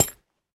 latest / assets / minecraft / sounds / block / chain / step4.ogg
step4.ogg